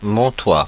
Ääntäminen
Ääntäminen France (Paris): IPA: /mɔ̃.twa/ Haettu sana löytyi näillä lähdekielillä: ranska Käännöksiä ei löytynyt valitulle kohdekielelle.